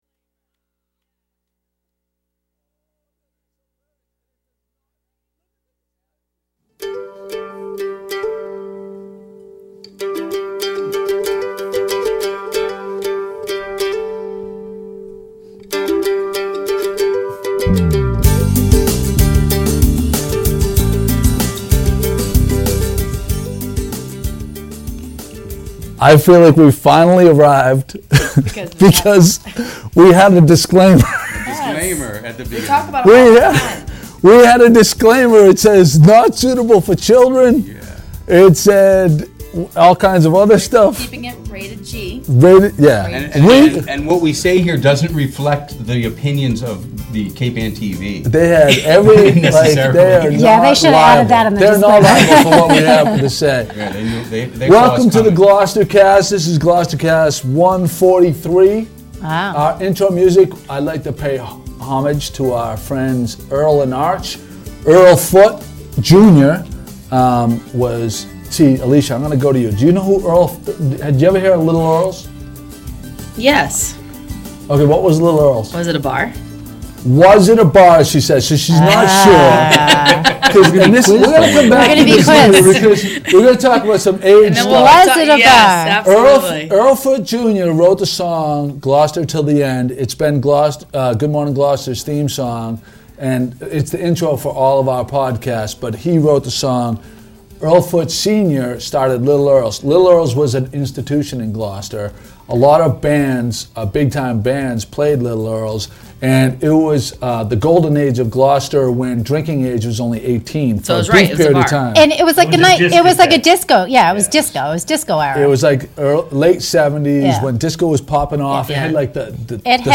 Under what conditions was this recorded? GloucesterCast143TapedAtCapeAnnTV